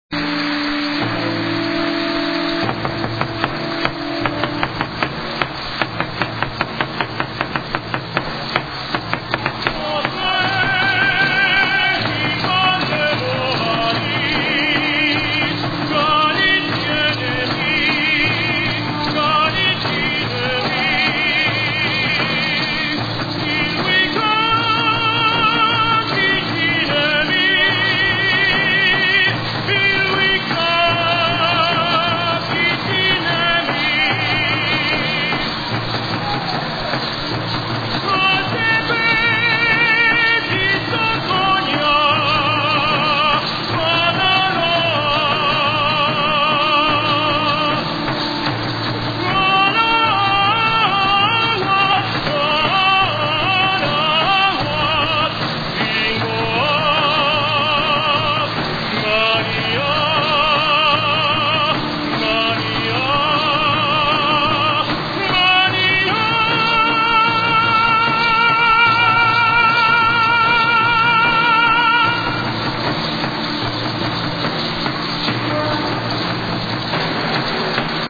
Msza Święta kanonizacyjna
Po kanonizacji Juana Diego, Indianie ubrani w tradycyjne stroje azteckie, wykonali taniec ku czci nowego Świętego do pięknej pieśni Maryjnej, której towarzyszyły dźwięki bębnów, muszli i rogów.
105 kB mp3 -Pieśń Maryjna do tanecznego pochodu Indian